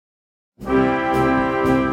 Gattung: Konzertstück für Jugendblasorchester
Besetzung: Blasorchester